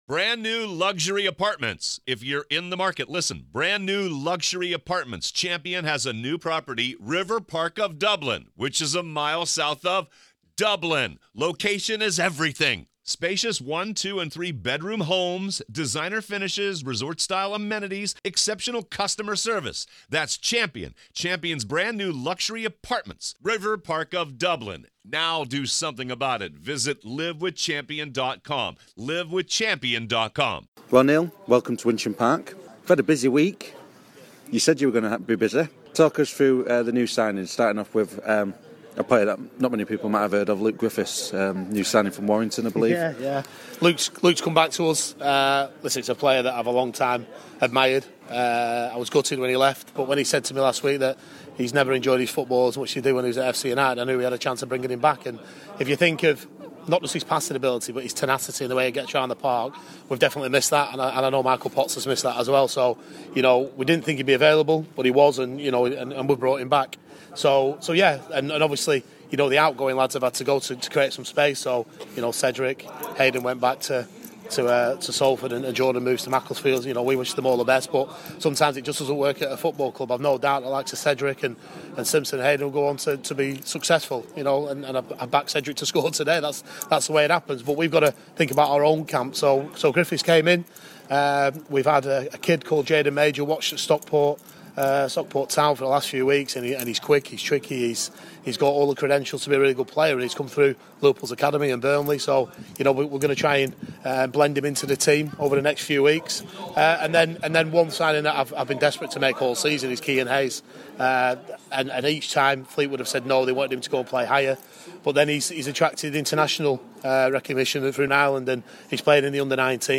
Pre Match Interview